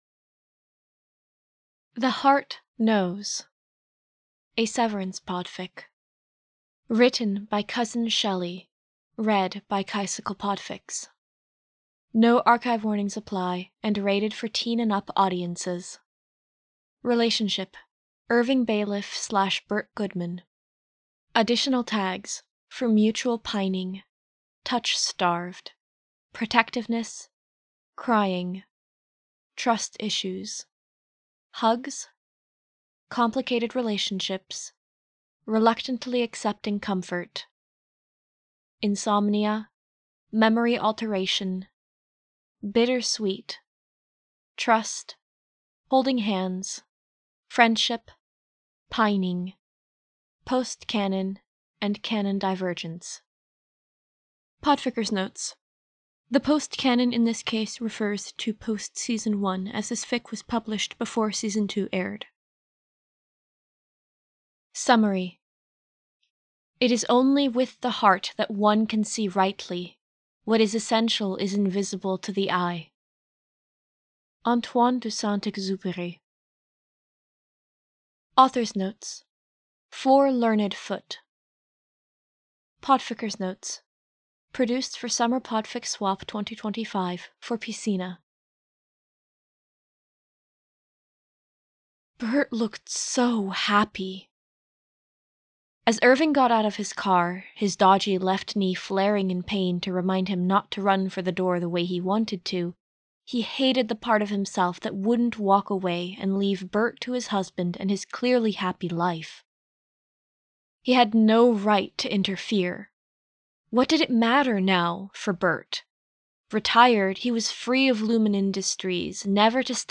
The Heart Knows [Podfic]
The_Heart_Knows-no-music.mp3